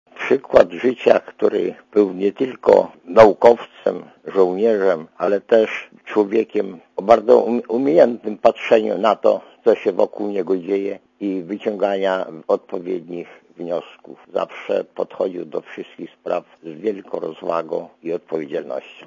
Jego życie może być przykładem dla każdego z nas - wspomina ostatni prezydent RP na uchodźstwie Ryszard Kaczorowski.